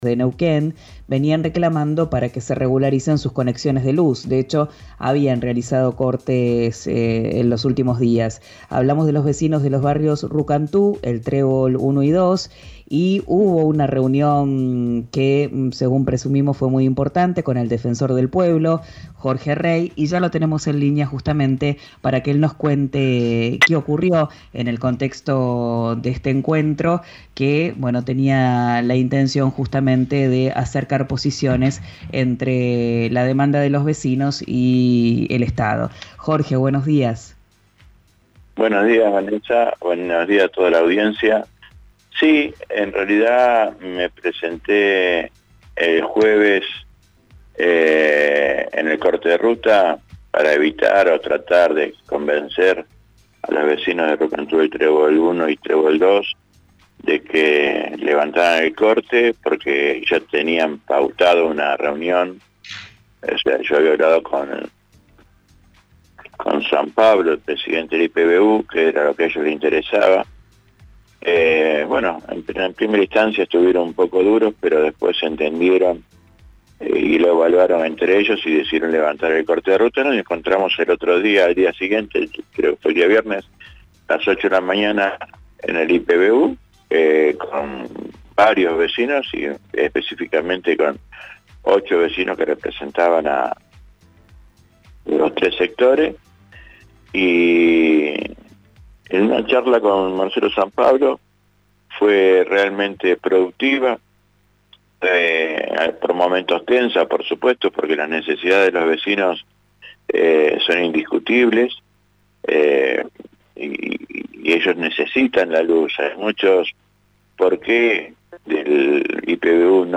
Escuchá al Defensor del Pueblo de Neuquén, Jorge Rey, en RÍO NEGRO RADIO: